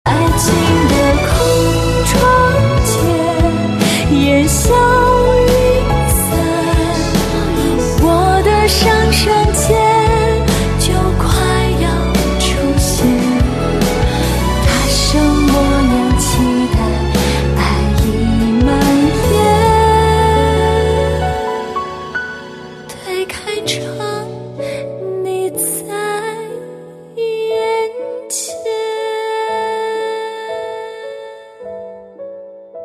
M4R铃声, MP3铃声, 华语歌曲 91 首发日期：2018-05-15 12:24 星期二